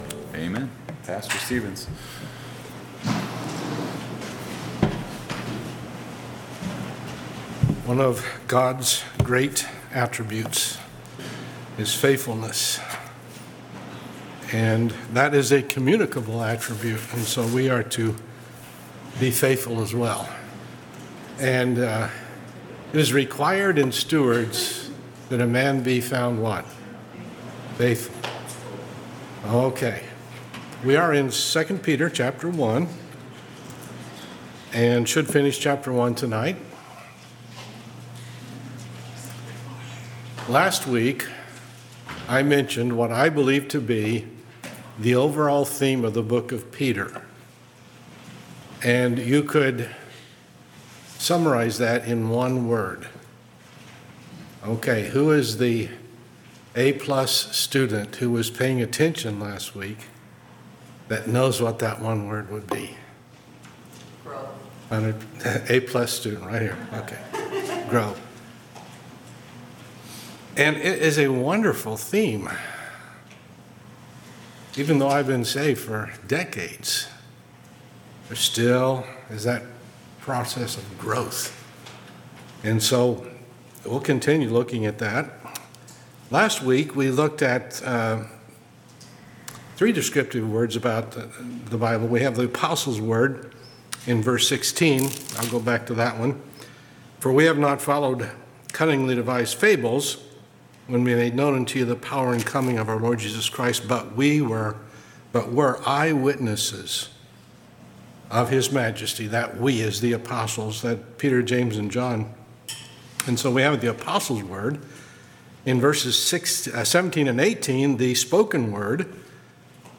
Wednesday Night Service